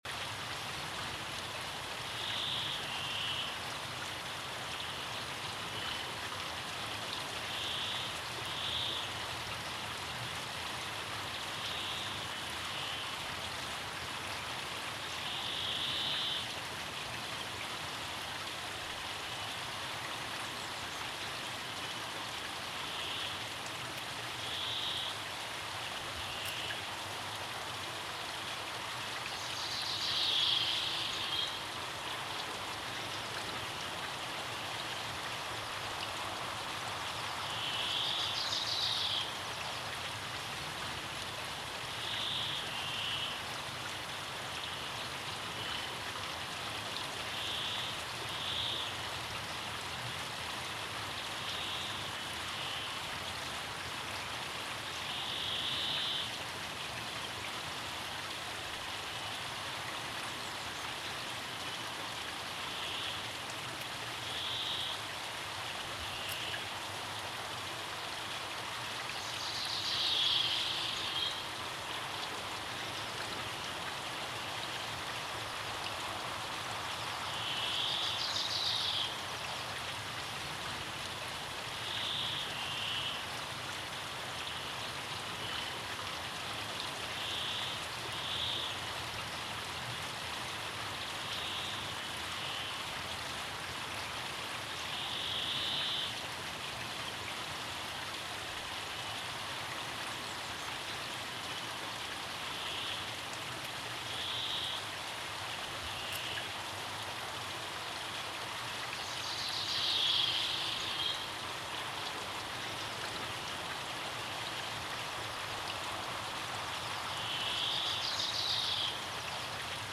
Ruido blanco nórdico
Contenido: Paseando por el río Ume. El audio reproduce el sonido ambiente de un paseo por la orilla del río Ume, cuarenta segundos en bucle.